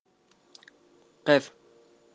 En mi caso, la letra maldita fue la ق, que no conseguí pronunciar hasta que me di cuenta de que suena muy parecido al cacareo de las gallinas mientras picotean el grano: qoqoqoqo.